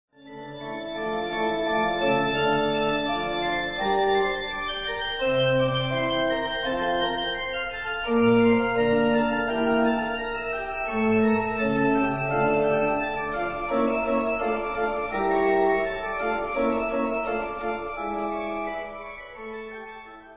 kousek fugy (vyznačená část s Fly me zazní v rozmezí 2.- 13. sekundy), pusťte si i
Fugue-sample.mp3